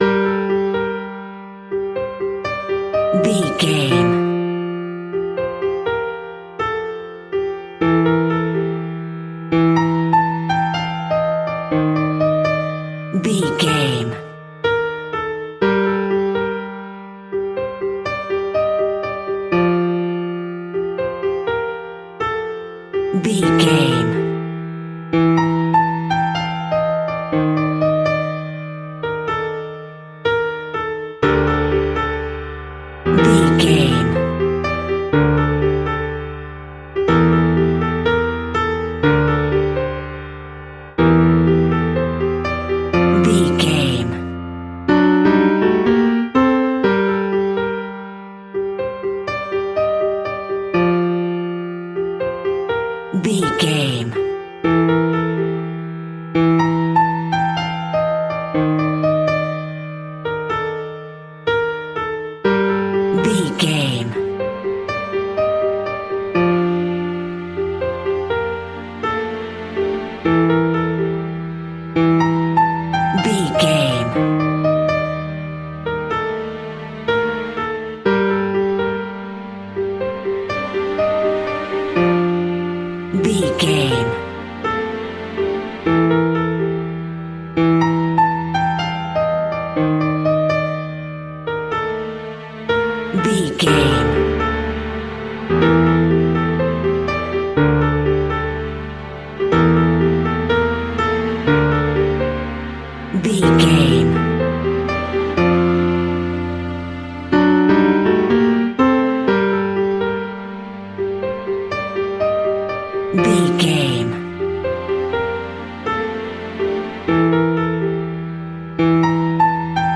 Haunted Thriller Music Sounds.
Aeolian/Minor
ominous
suspense
eerie
piano
strings
synth
pads